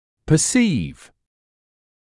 [pə’siːv][пэ’сиːв]воспринимать, понимать, осозновать